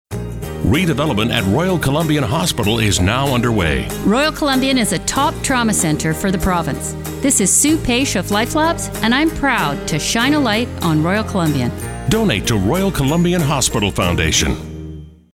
Spring 2017 redevelopment radio ads, featuring campaign cabinet volunteers